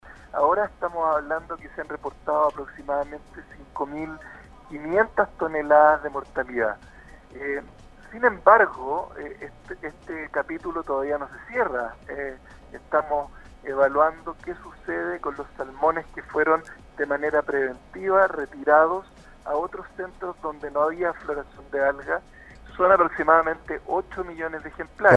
En conversación con Radio Sago el Superintendente de Medio Ambiente, Cristóbal de La Maza, señaló que la empresa Camanchaca reportó la mortalidad hace varios días atrás y de acuerdo a los planes de contingencia el retiro debe ser mucho más expedito y ante los retrasos de su programa de contingencia la SMA exigió como medida provisional un plazo último para cumplir con el retiro de mortalidad. El Superintendente puntualizó que hay obligaciones que cumplir y una investigación en curso para confirmar o descartar los planteamientos de la empresa durante este evento, pero están abocados al control de la emergencia.